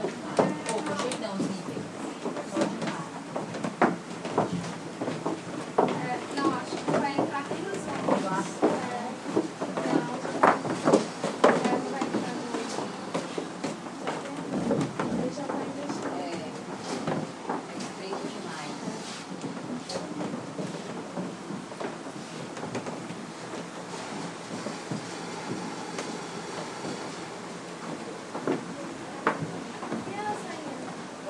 Passos no andar de cima
Passos de pessoas no andar de cima de velho casarão. Subsolo de um galpão de lojas no Centro de Fortaleza.